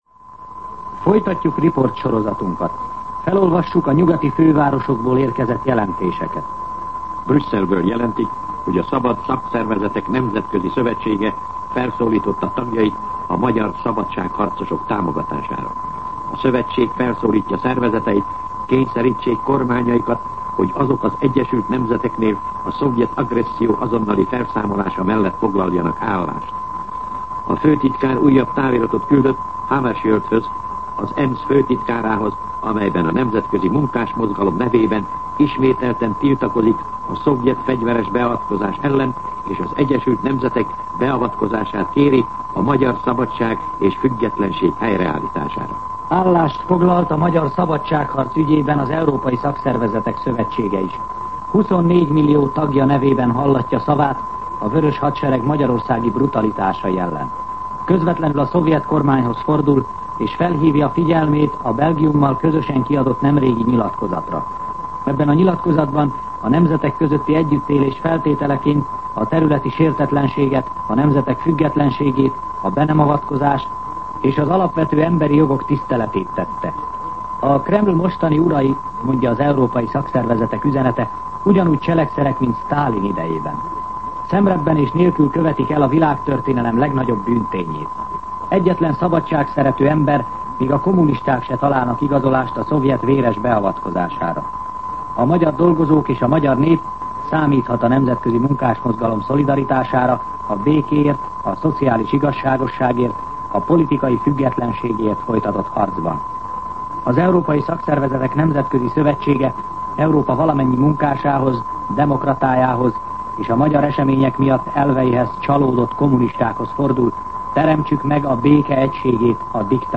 MűsorkategóriaTudósítás